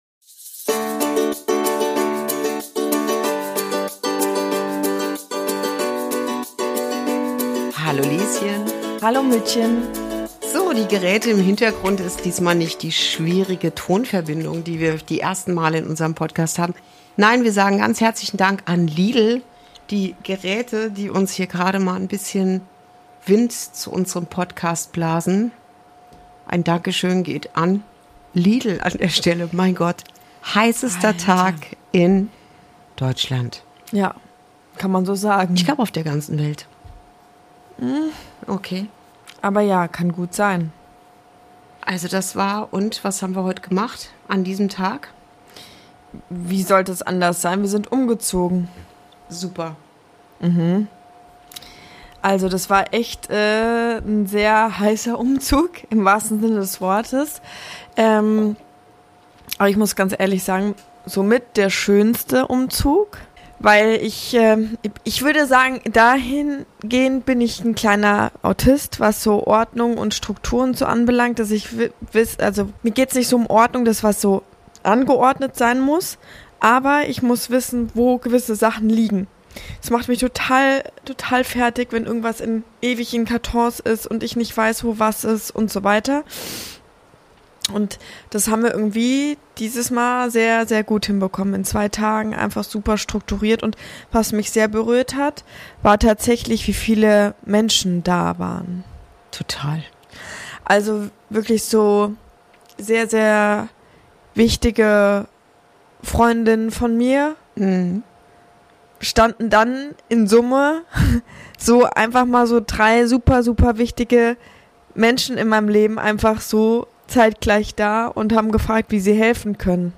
Beschreibung vor 9 Monaten Diese Folge ist ein Wohnzimmergespräch mitten aus dem Leben.
Und wie immer: viel Liebe, echte Tiefe – und ein bisschen Sommerwind vom Ventilator.